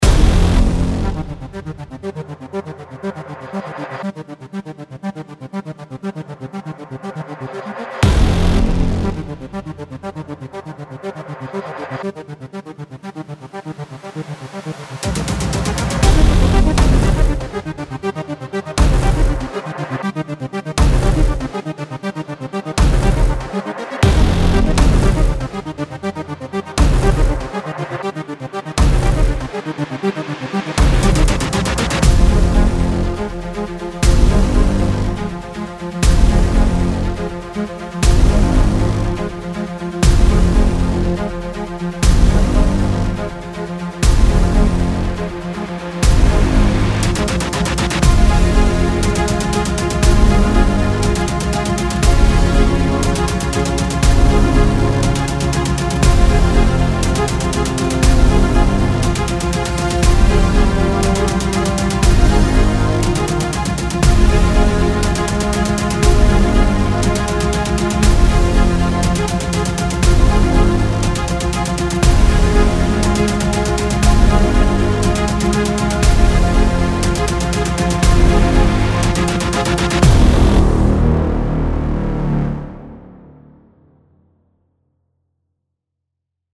圈，脉动合成器，有节奏的打击乐等等。